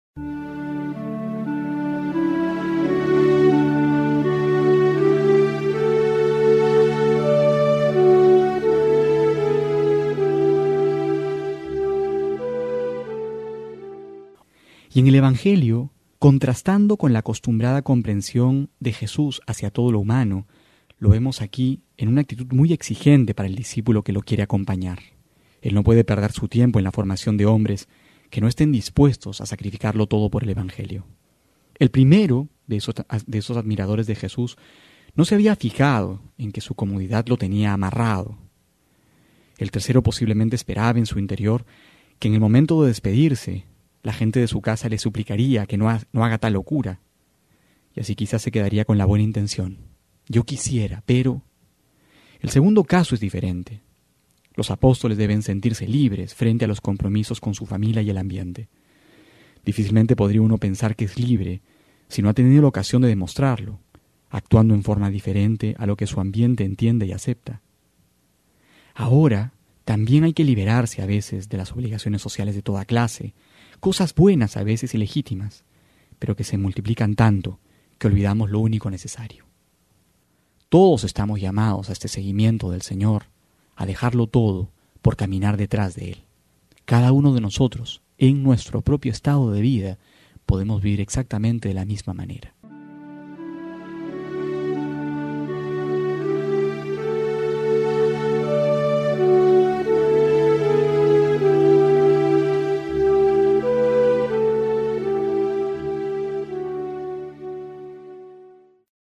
octubre03-12homilia.mp3